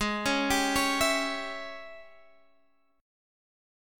G#M7sus4#5 Chord